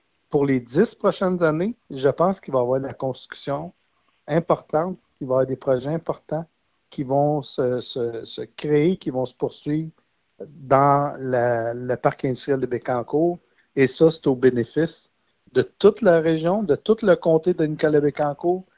En entrevue, le député de Nicolet-Bécancour a rappelé l’impact qu’une telle annonce aura sur la région.